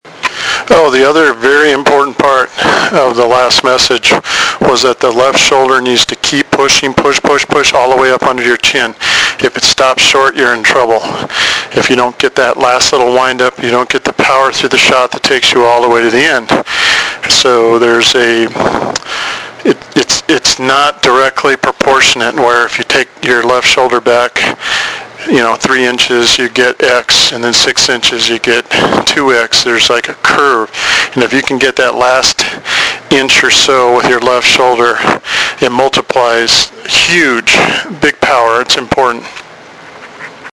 This was essentially taking audio notes as my method progressed.